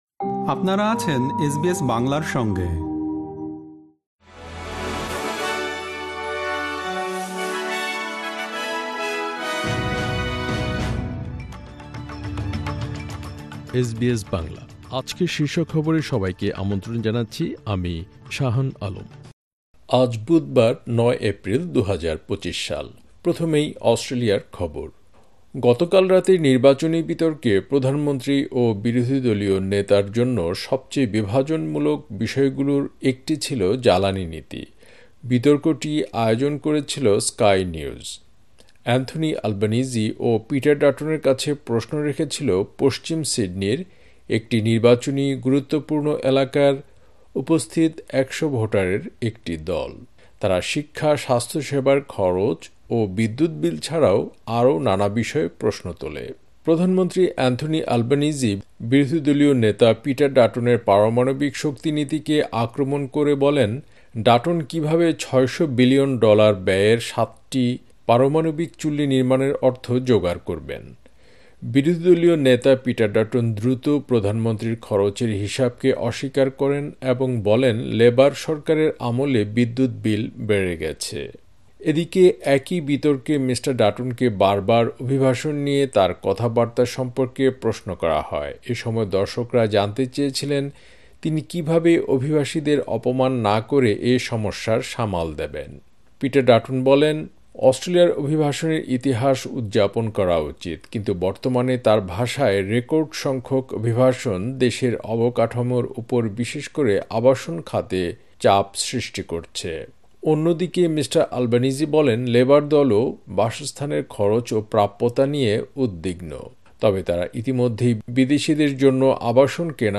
এসবিএস বাংলা শীর্ষ খবর: ৯ এপ্রিল, ২০২৫